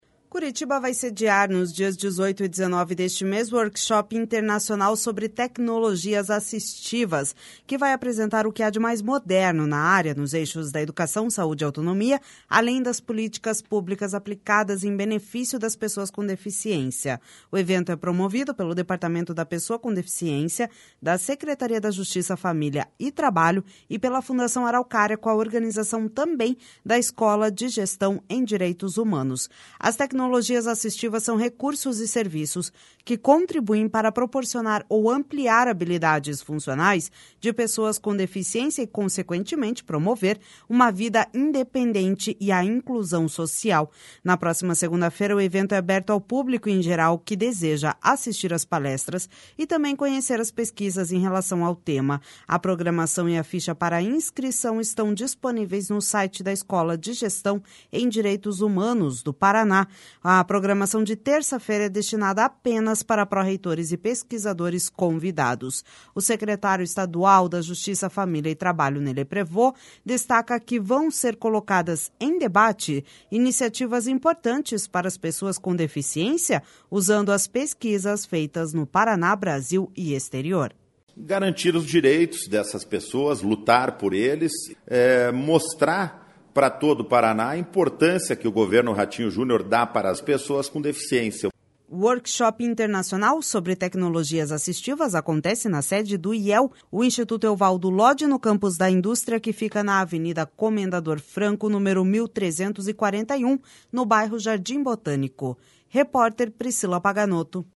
O secretário estadual da Justiça, Família e Trabalho, Ney Leprevost, destaca que vão ser colocadas em debate iniciativas importantes para as pessoas com deficiência, usando as pesquisas feitas no Paraná, Brasil e Exterior.// SONORA NEY LEPREVOST//O Workshop Internacional sobre Tecnologias Assistivas acontece na sede do IEL, o Instituto Euvaldo Lodi, no Campus da Indústria, que fica na Avenida Comendador Franco, número 1341, no bairro Jardim Botânico.